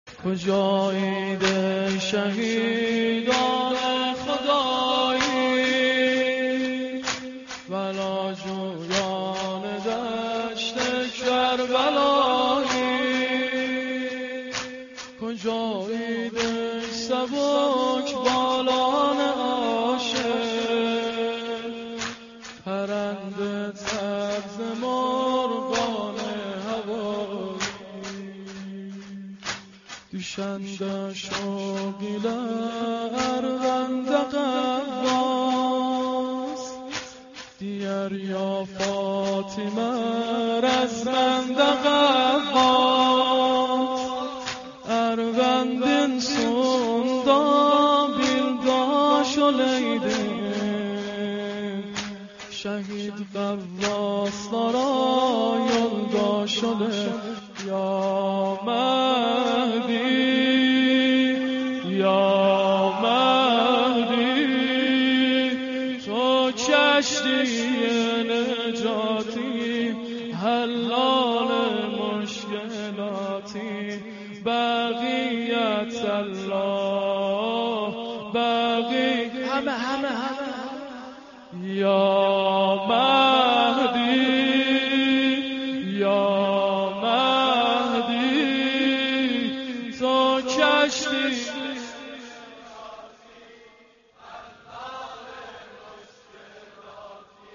maddahi-244.mp3